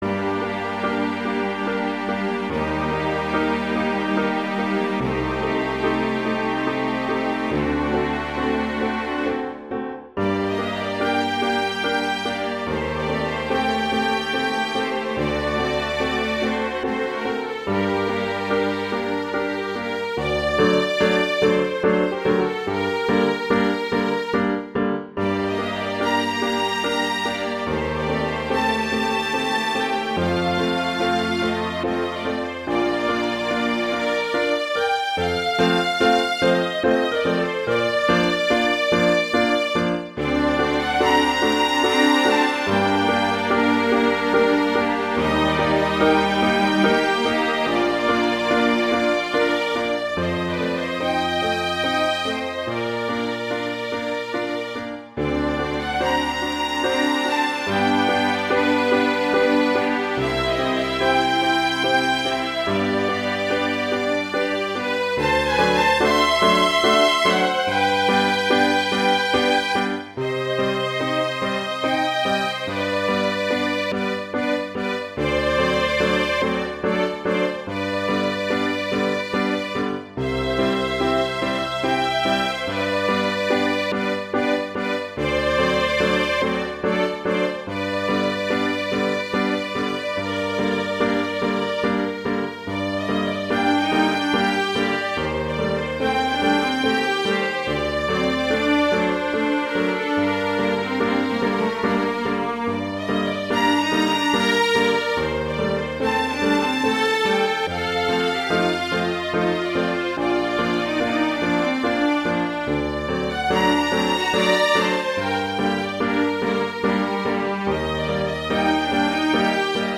classical
G minor
♩=72 BPM